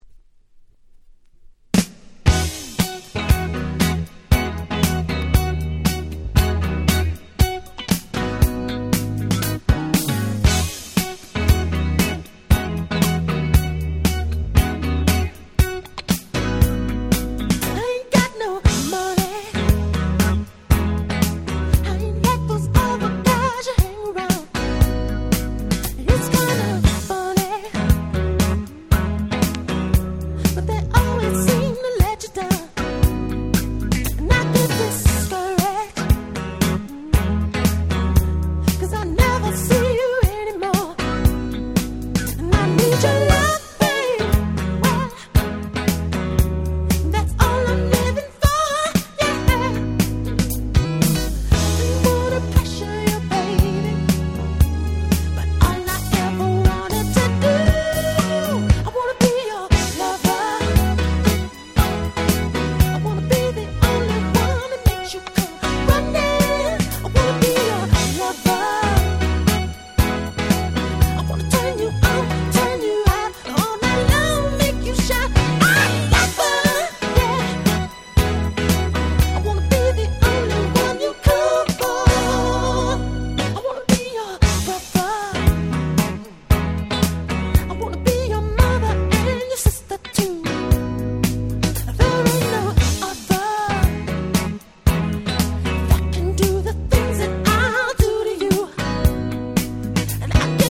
79' Super Hit Disco / Dance Classics !!